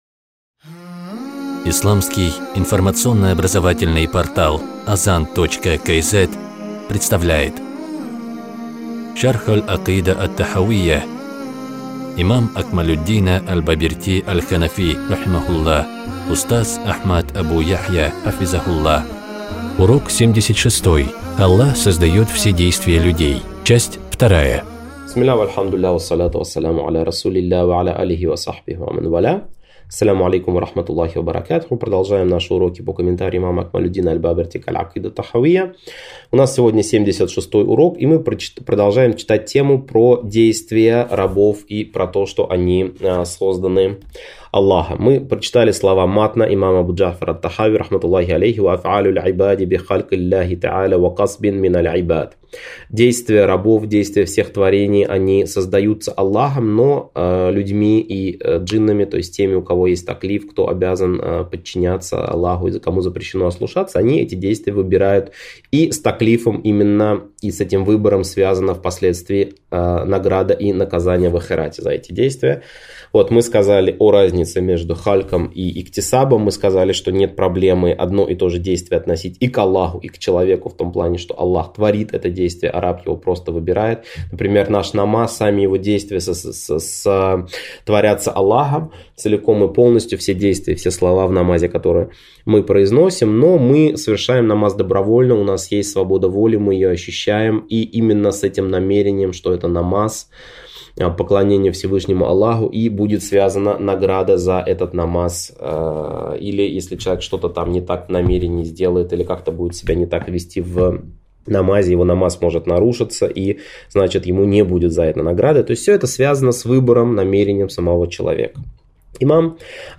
Цикл уроков по акыде второго уровня сложности, рассчитанный на слушателя, освоившего основы акыды.